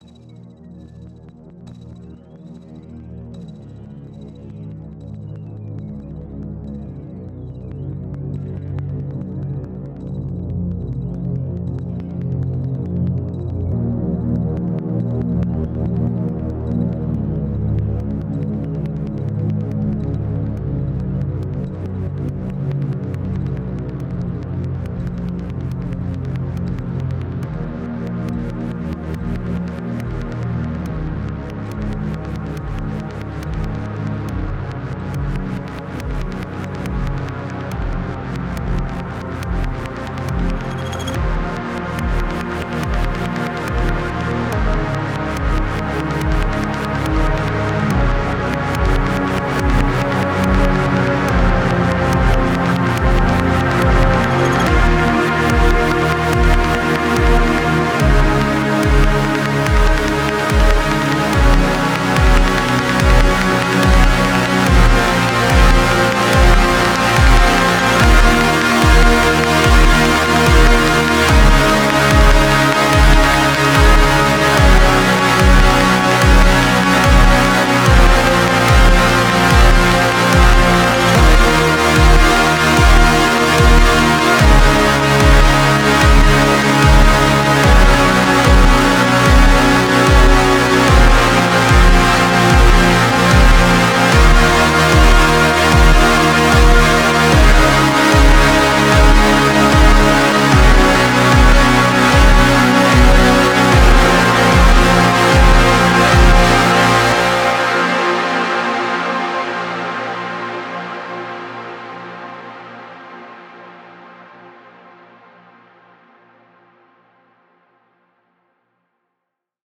Tags: powerfull, synths, rich, dark
Tempo: 140 BPM (4/4) Key: D# minor